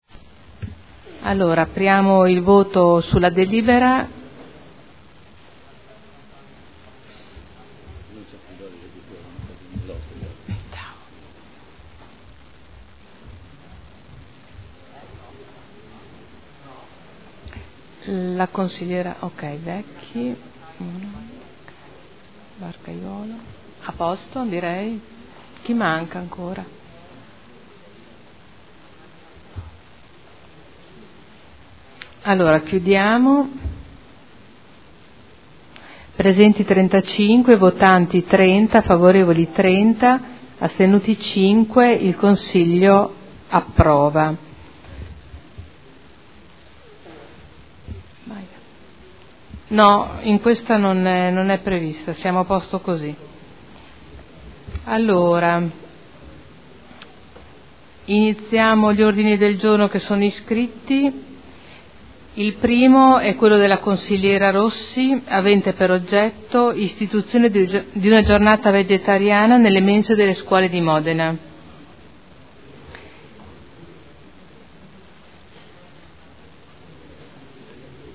Presidente — Sito Audio Consiglio Comunale
Seduta del 31 marzo. Proposta di deliberazione: Proposta di progetto - Ampliamento del Centro Medicina Rigenerativa – Via Gottardi – Z.E. 473 area 01 – Nulla osta in deroga agli strumenti urbanistici comunali – Art. 20 L.R. 15/2013.